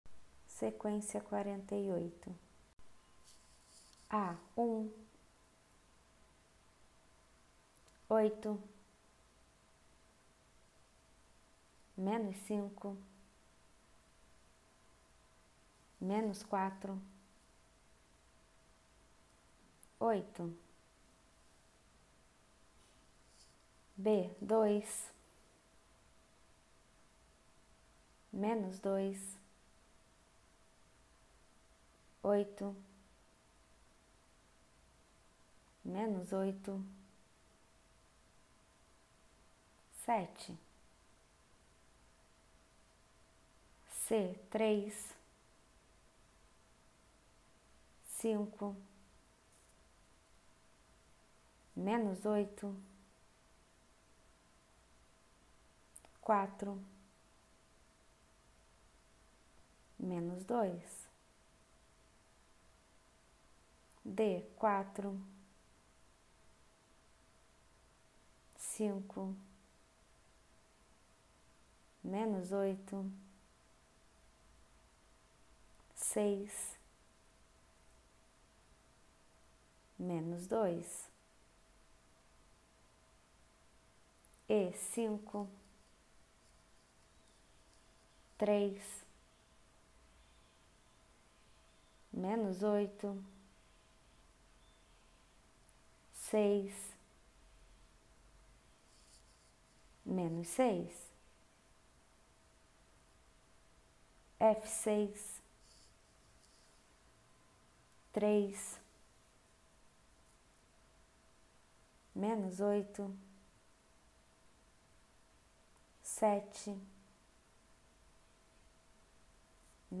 Ditado
Ditados sem troca de base - Lento